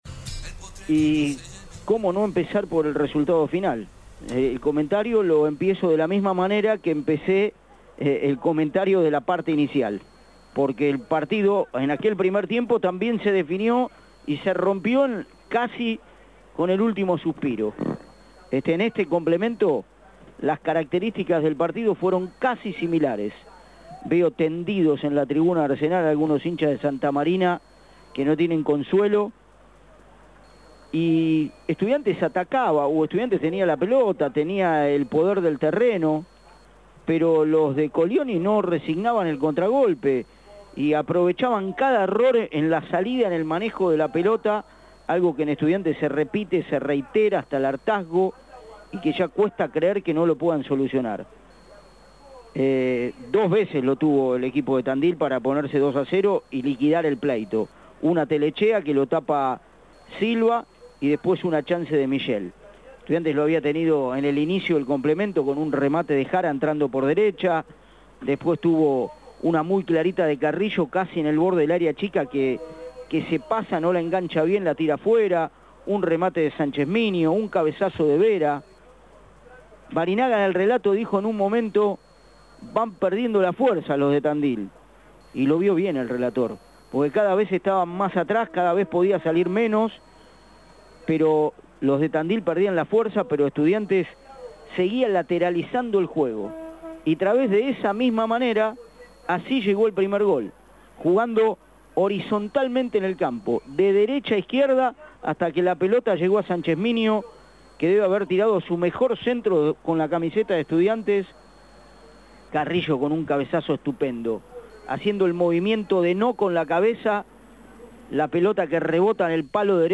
comentario-final-.mp3